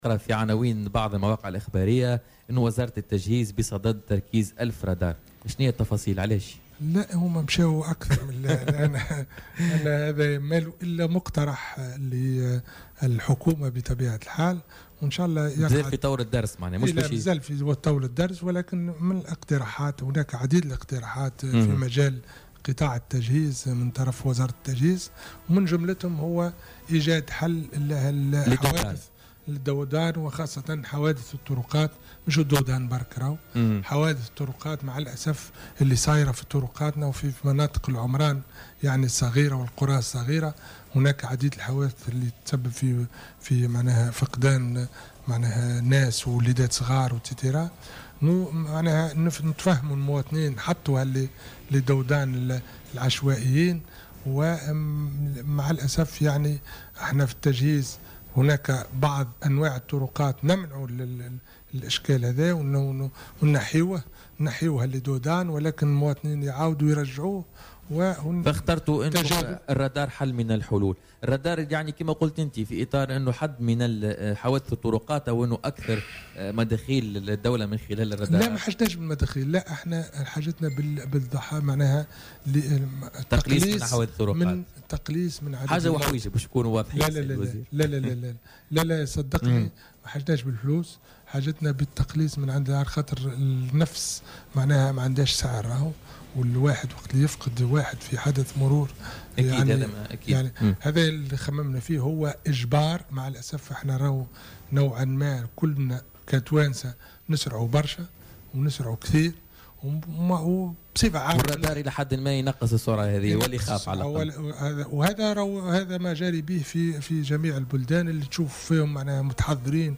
كما تحدّث الوزير في مداخلة له اليوم في برنامج "بوليتيكا" أيضا عن انتشار مخفضات السرعة العشوائية، مشيرا إلى أن الوزارة تعمل بصفة مستمرة على إزالتها.